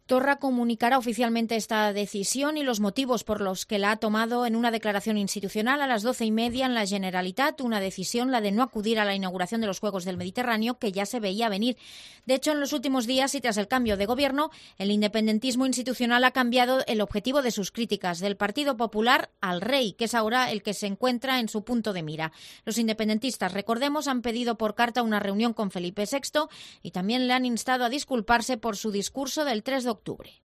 Torra piensa dar plantón al Rey en la inauguración de los Juegos del Mediterráneo. Crónica